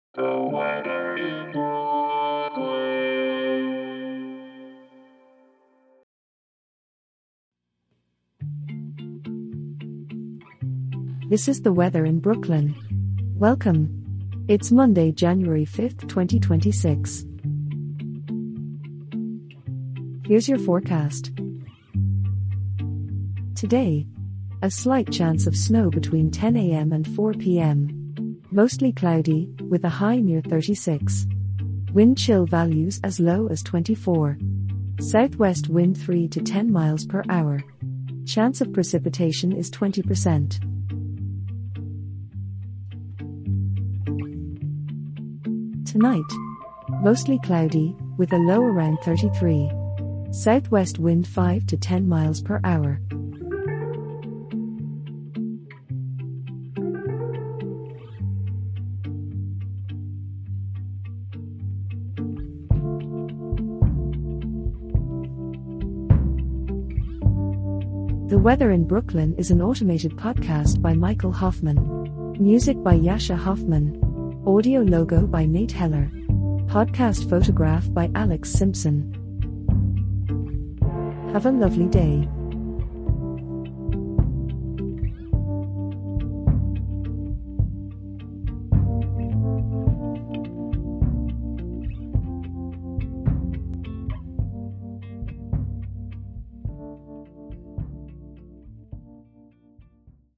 generated automatically